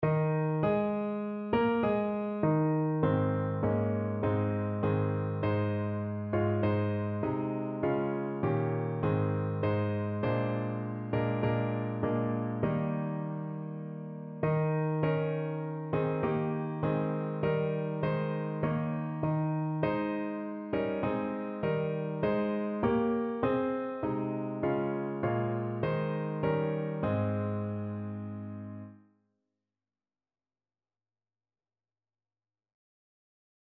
No parts available for this pieces as it is for solo piano.
Ab major (Sounding Pitch) (View more Ab major Music for Piano )
4/4 (View more 4/4 Music)
Instrument:
Piano  (View more Intermediate Piano Music)
Classical (View more Classical Piano Music)